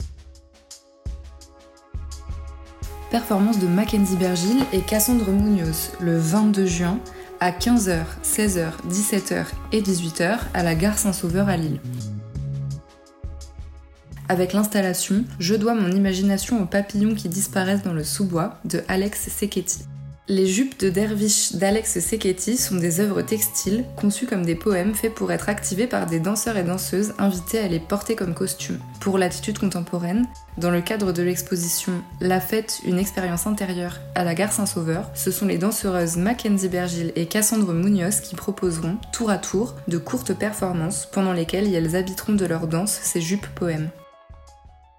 Version audio de la description du spectacle :